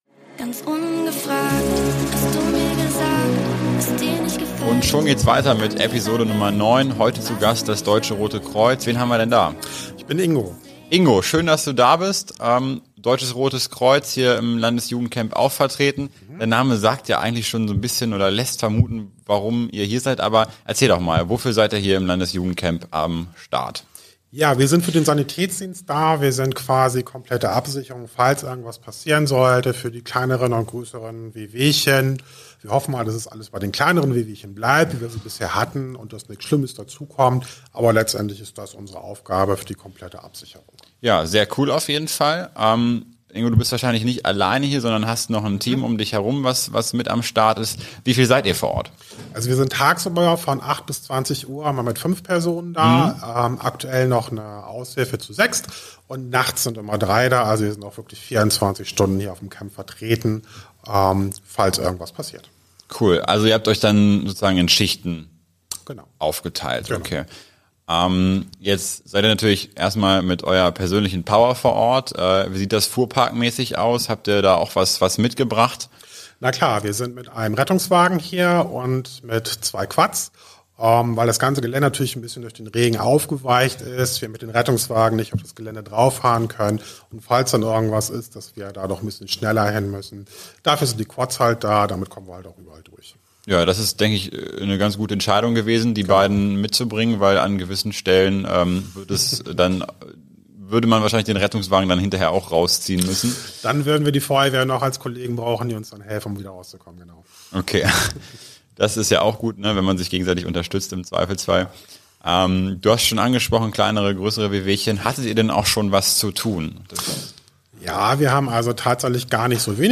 In Episode 9 des Podcasts wird das Interview mit dem Deutschen